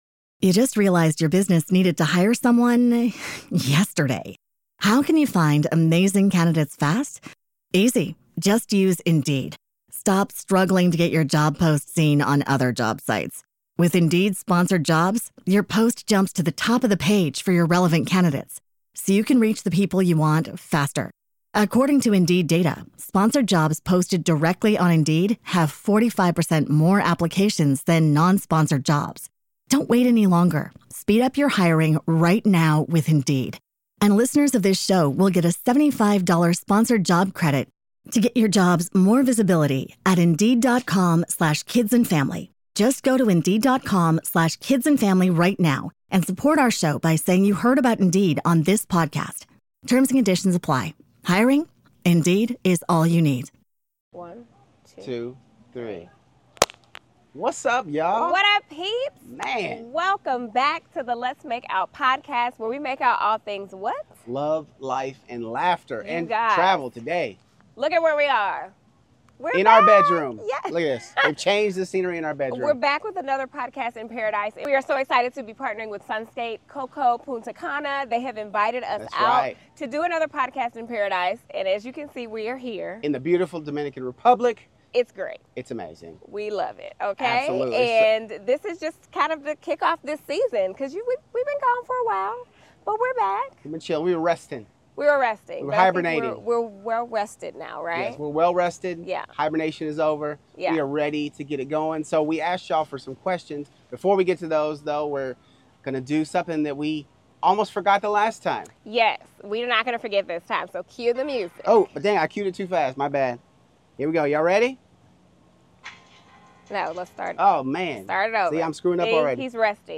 This episode is all brought to you from paradise! We are at the amazing Sunscape Coco Punta Cana Resort & Spa in the beautiful Dominican Republic!